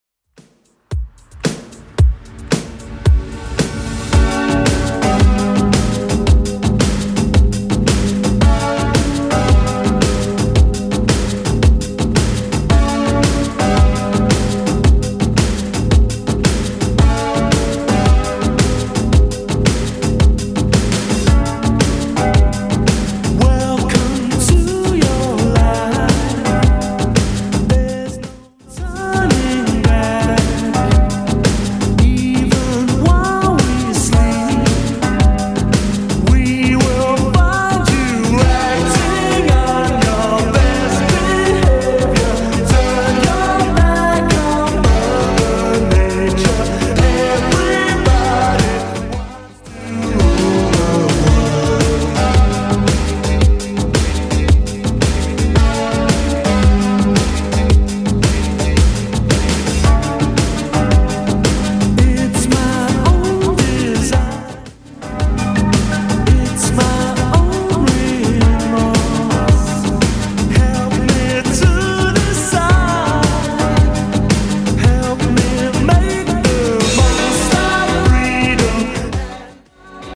Genre: 90's
BPM: 106